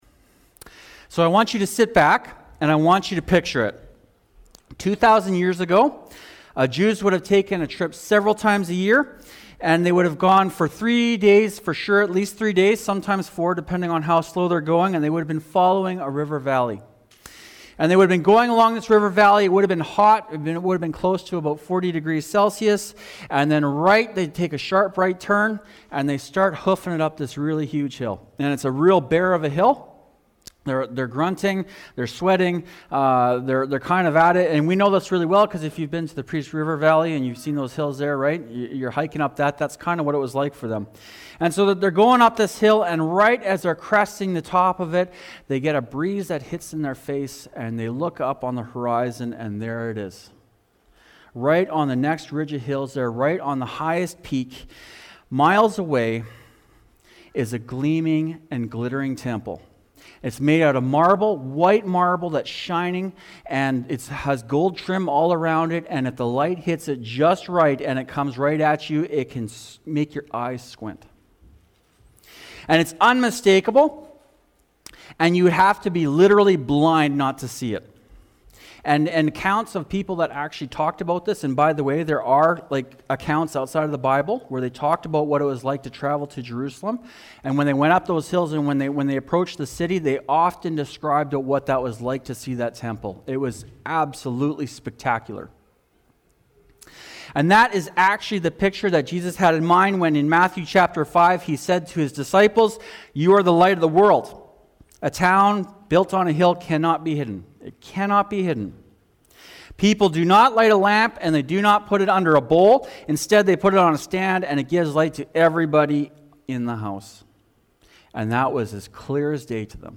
Shine-Sermon-June-7-Audio.mp3